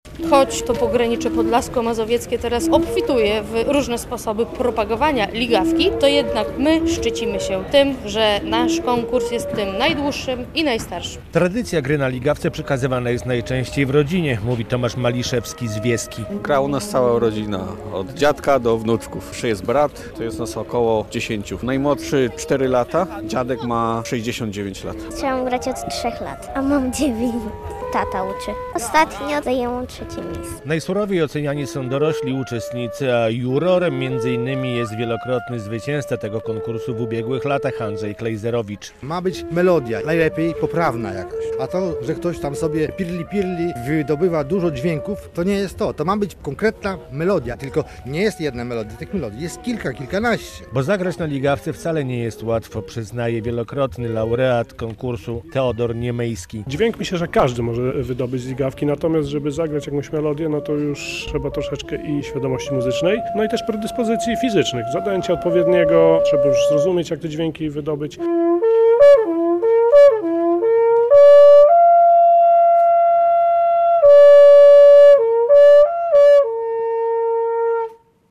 Pięćdziesięcioro uczestników - w czterech kategoriach wiekowych - zmierzyło się w Ciechanowcu w grze na ligawce. Już po raz 41. Muzeum Rolnictwa zorganizowało Konkurs Gry na Instrumentach Pasterskich.